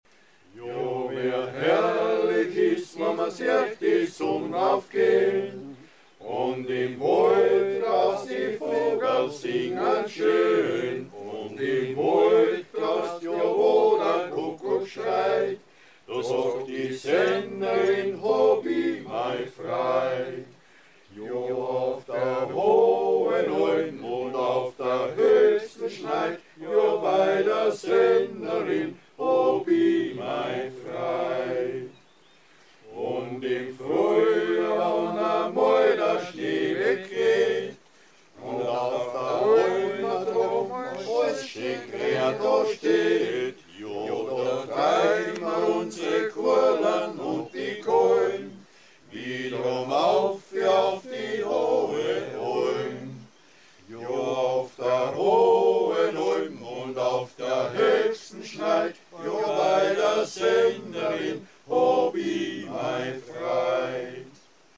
(Texte und Gesangsproben).
Auf der hohen Alm (Liedertafel Gusswerk)